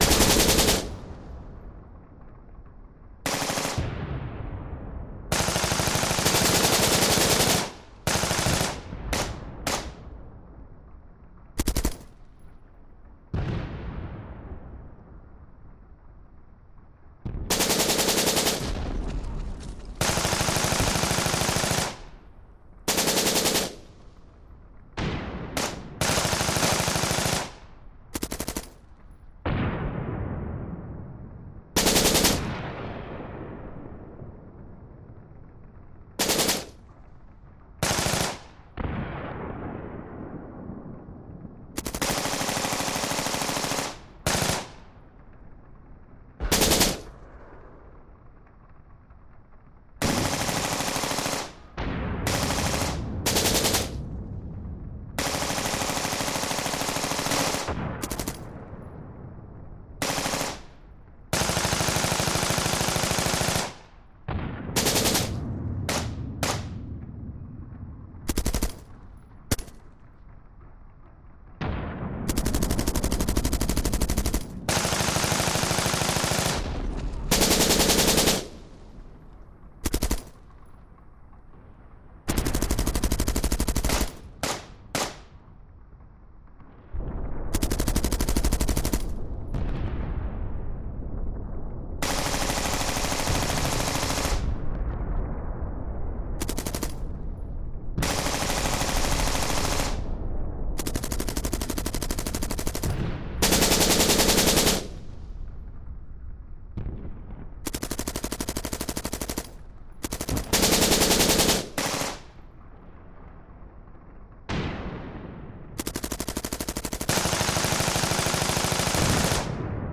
Грохот стрельбы на войне